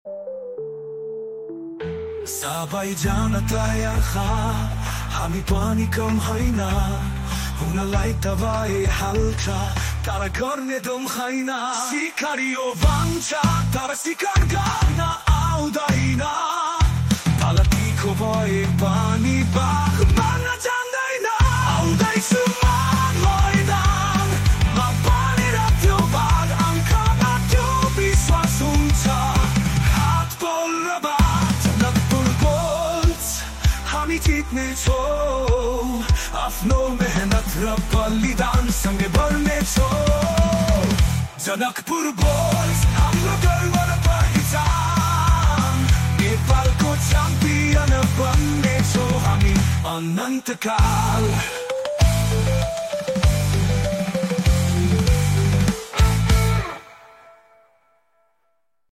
cover song